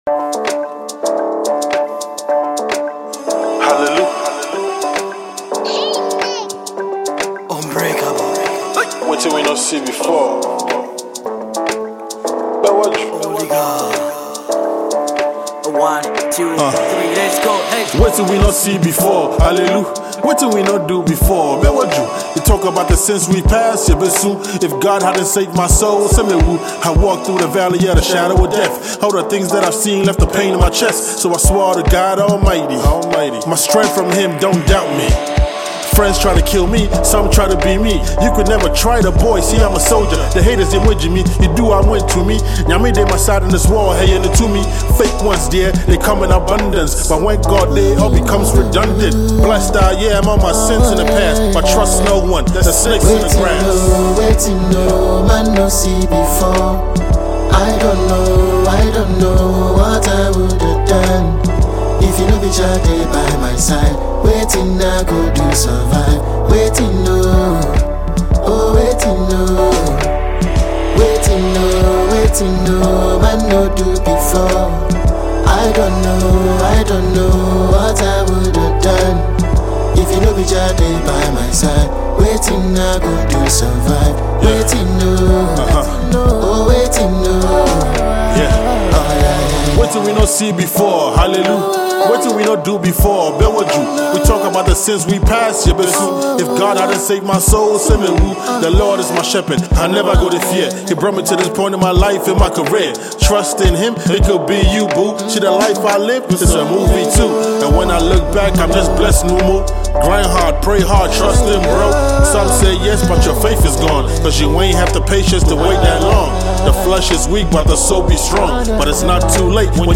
Ghanaian Hiphop recording artiste